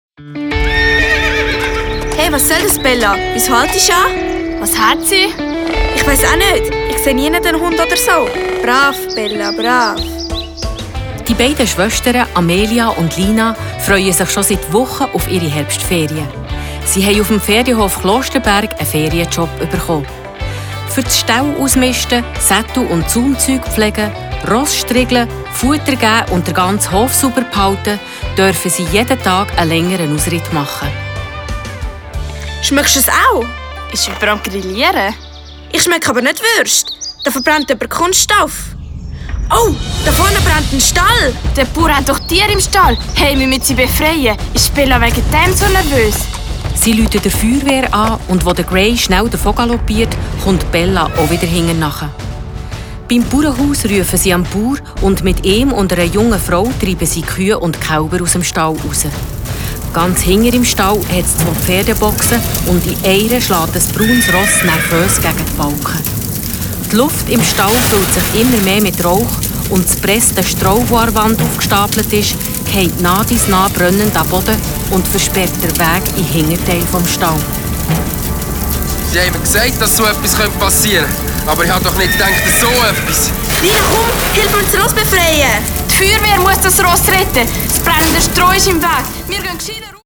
Dieses Hörspiel wurde von der Vereinigung zur Förderung der Schweizer Jugendkultur mit dem Label "Empfohlen" ausgezeichnet.